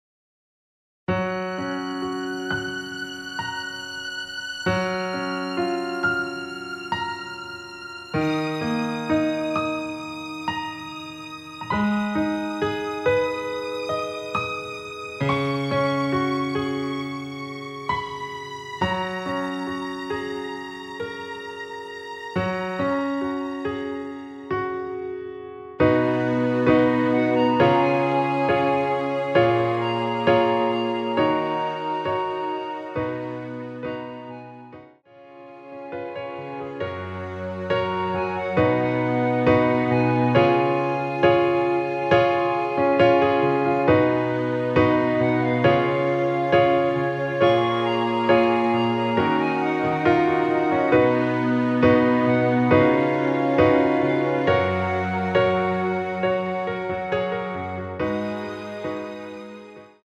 원키에서(+5)올린 멜로디 포함된 MR입니다.
F#
앞부분30초, 뒷부분30초씩 편집해서 올려 드리고 있습니다.
중간에 음이 끈어지고 다시 나오는 이유는